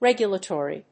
音節reg・u・la・to・ry 発音記号・読み方
/régjʊlət`ɔːri(米国英語), ˈregjʌlʌˌtɔ:ri:(英国英語)/